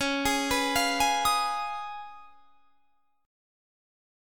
Listen to C#9 strummed